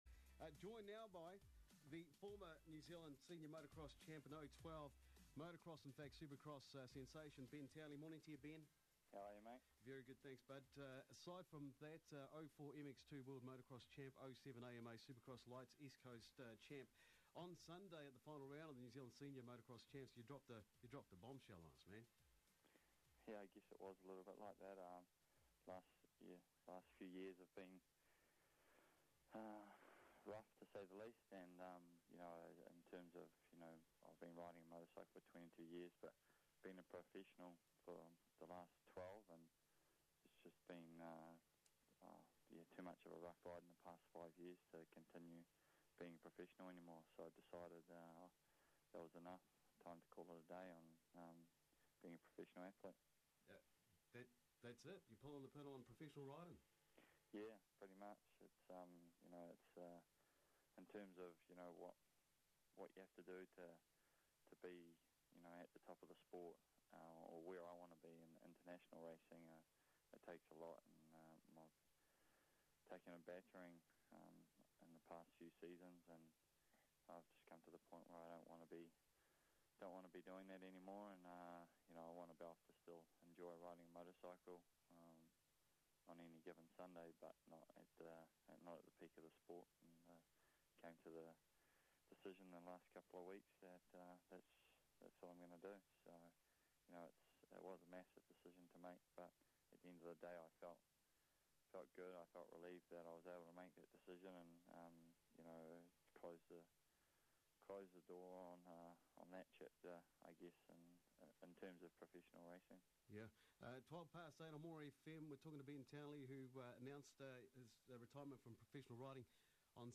Click HERE for audio interview Townley’s remarkable journey saw him leave his home country of New Zealand to take on the world at just 16 years of age.